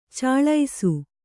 ♪ caḷḷisu